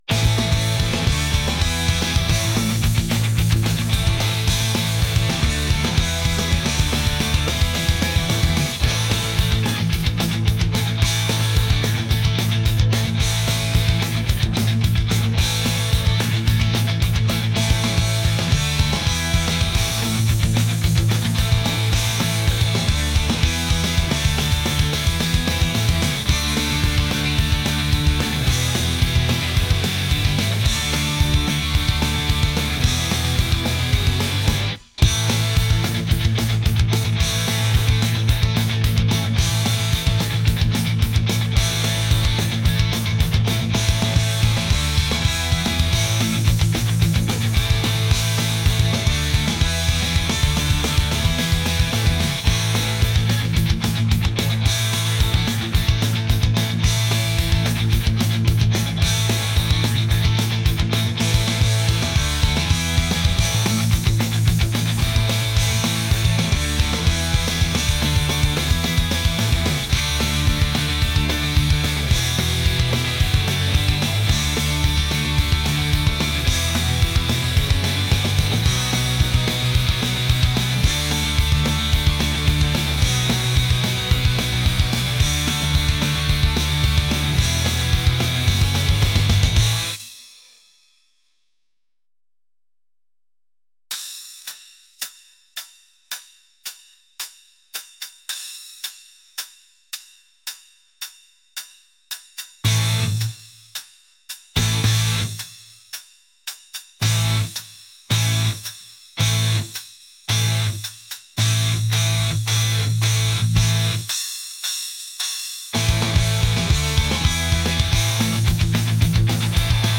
punk | rock | energetic